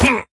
Minecraft Version Minecraft Version 1.21.5 Latest Release | Latest Snapshot 1.21.5 / assets / minecraft / sounds / mob / vindication_illager / hurt1.ogg Compare With Compare With Latest Release | Latest Snapshot
hurt1.ogg